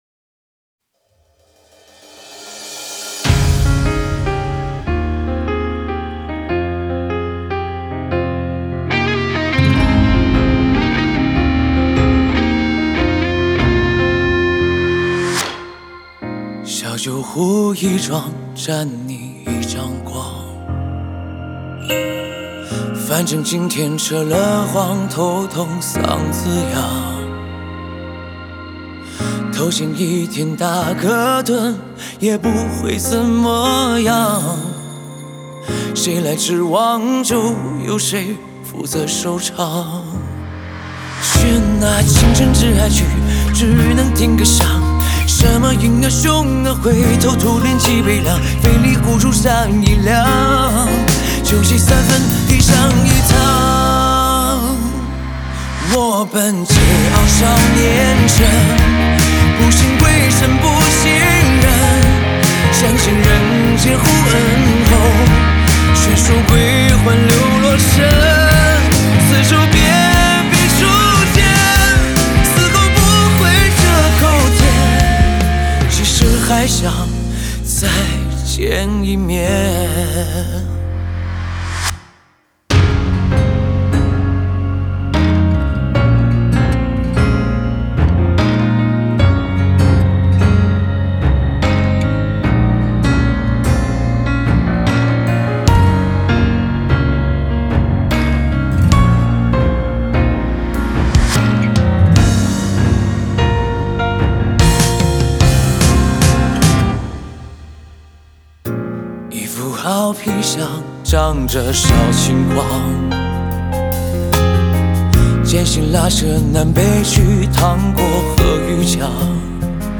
吉他
和声